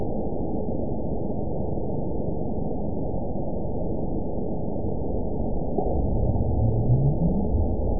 event 917361 date 03/29/23 time 01:15:01 GMT (2 years, 1 month ago) score 9.60 location TSS-AB01 detected by nrw target species NRW annotations +NRW Spectrogram: Frequency (kHz) vs. Time (s) audio not available .wav